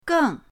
geng4.mp3